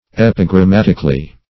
Search Result for " epigrammatically" : The Collaborative International Dictionary of English v.0.48: Epigrammatically \Ep`i*gram*mat"ic*al*ly\, adv. In the way of epigram; in an epigrammatic style.